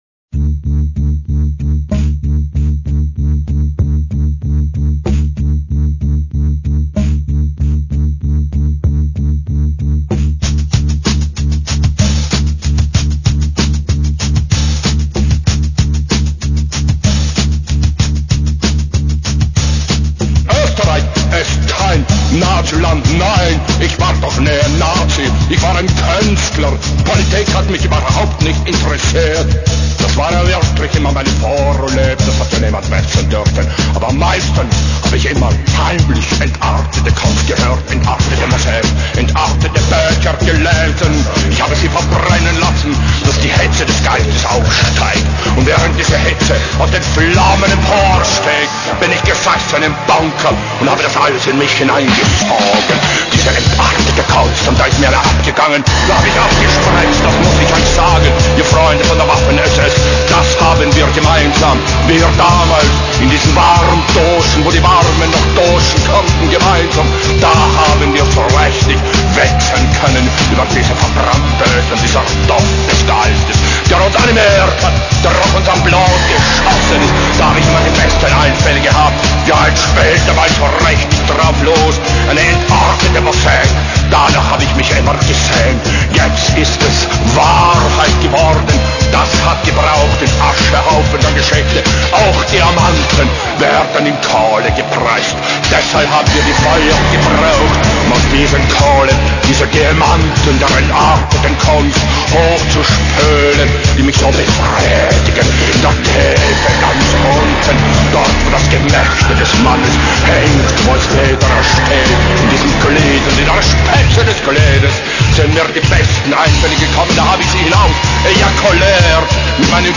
1,2 MB - mono - 5:10